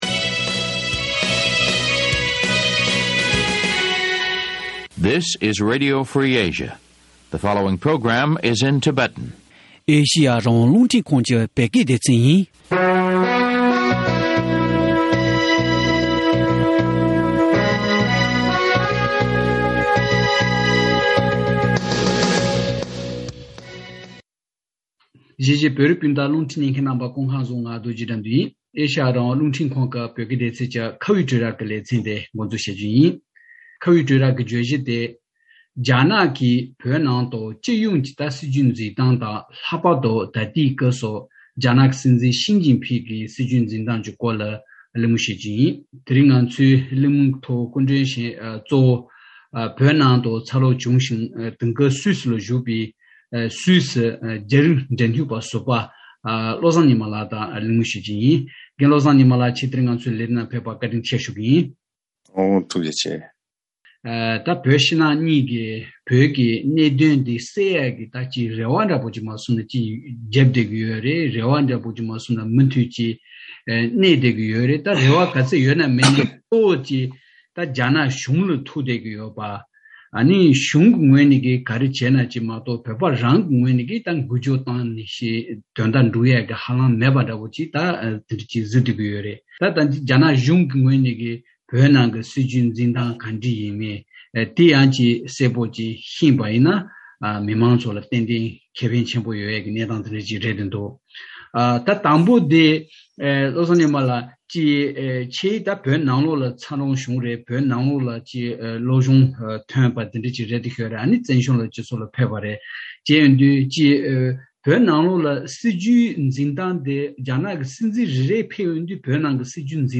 རྒྱ་ནག་གིས་བརྗོད་པའི་བོད་ནང་དཔལ་འབྱོར་འཛུགས་སྐྲུན་གོང་འཕེལ་དང་ཞི་བདེ་བརྟན་ལྷིང་རྒྱུན་འཁྱོངས་ཞེས་པ་དེ་གང་འདྲ་ཞིག་ལ་གོ་དགོས་མིན་སྐོར་བགྲོ་གླེང་ཞུས་པ།